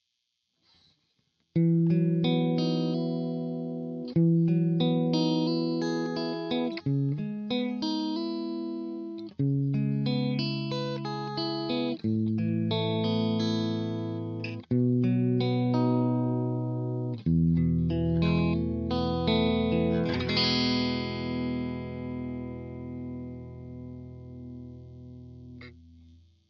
それで音はとてもクリアです、僕の機体だからかもしれませんが歪みよりクリーン系はホントに素敵な音がします
リア・フロント・センターの順で弾いてみました
何も音の装飾はしていない素の音です